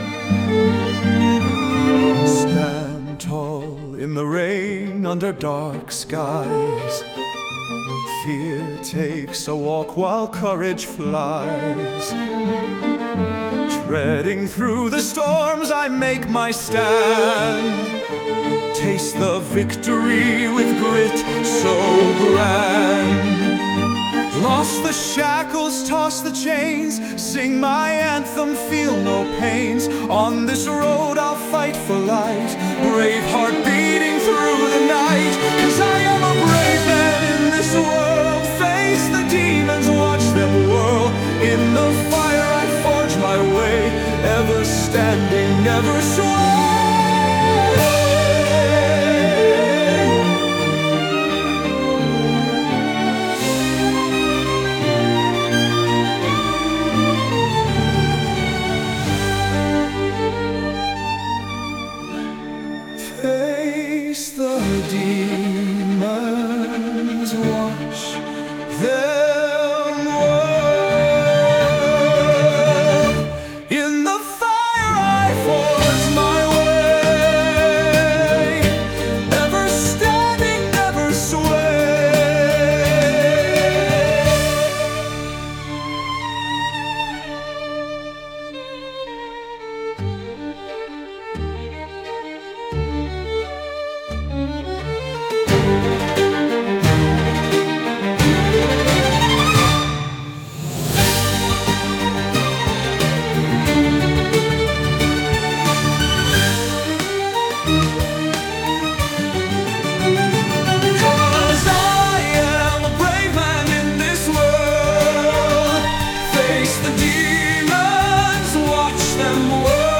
タイトル通り、困難に立ち向かう「勇敢な心」を表現した、ダイナミックでドラマチックな一曲。
魂を揺さぶるような力強い男性ボーカルと、感情を昂らせるバイオリンの美しい旋律が、壮大な物語を紡ぎ出します。